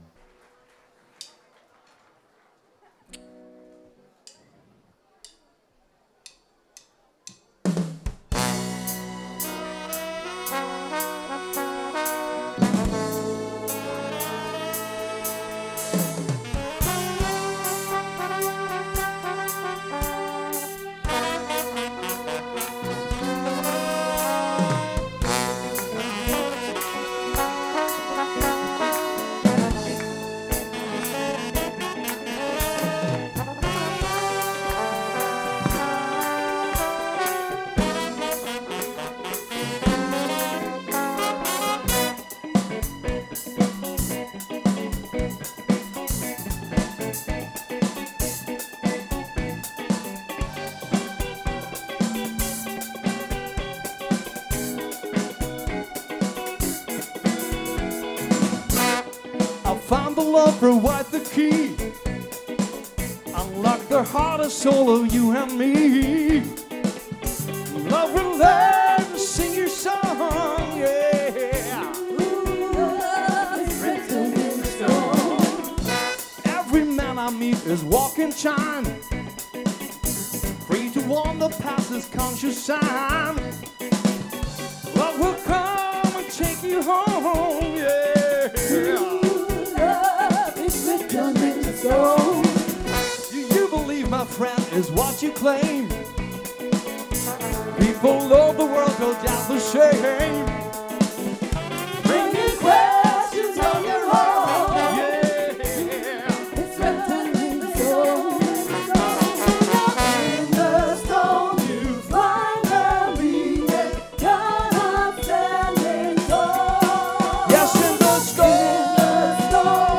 · Genre (Stil): Soul